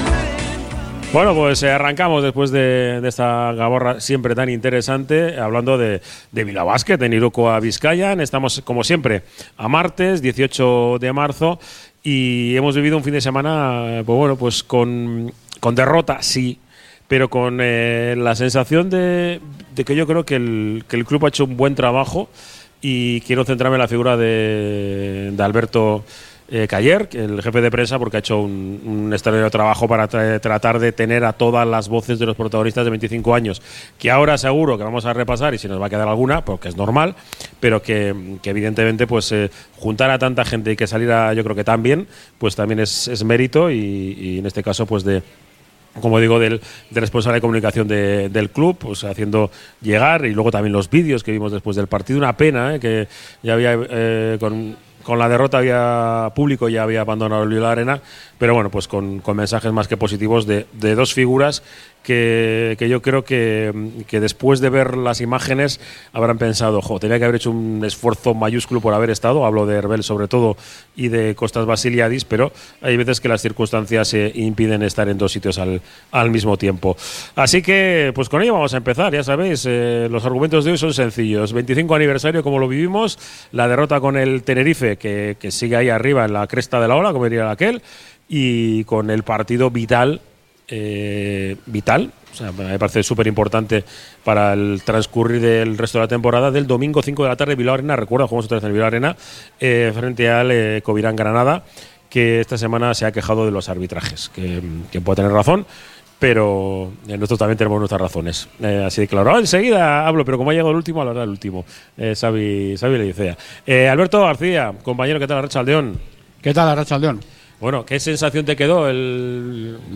Desde el Bar Izar la Quinta Estrella